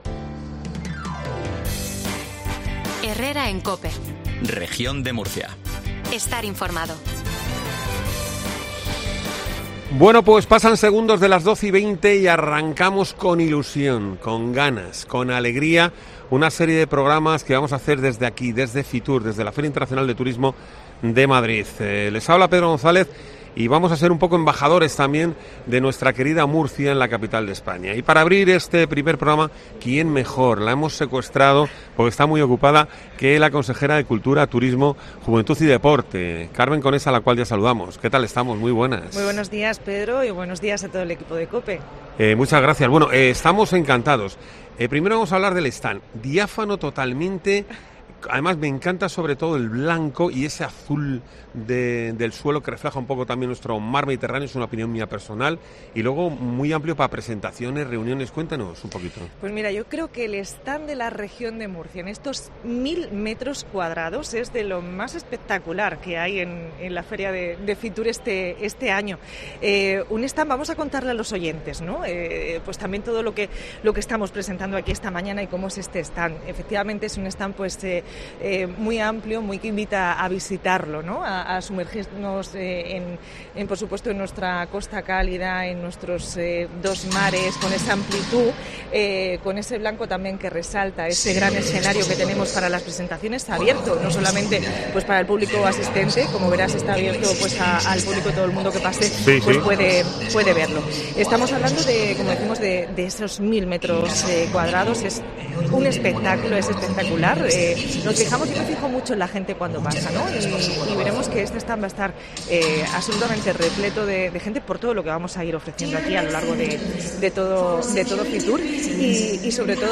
Carmen Conesa, consejera de Turismo, Cultura, Juventud y Deportes, ha inaugurado el set de COPE Región de Murcia en Fitur 2024.